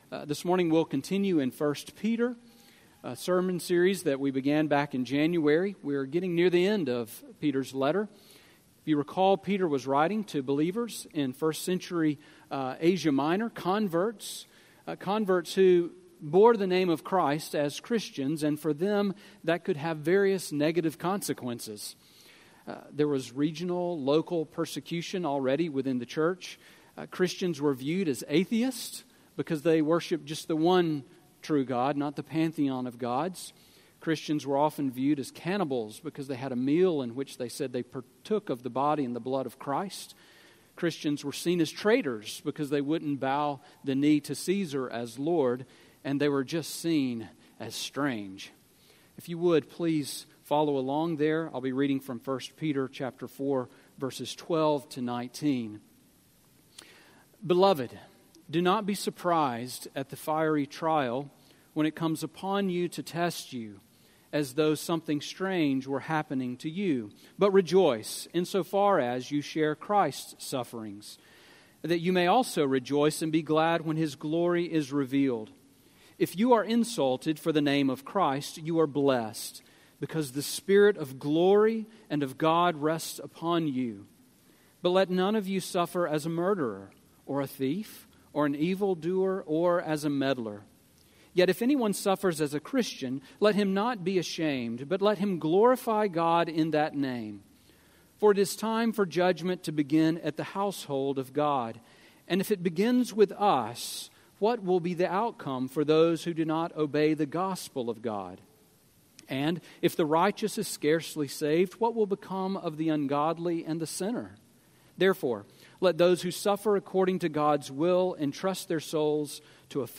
Sermon on 1 Peter 4:12-19 from April 17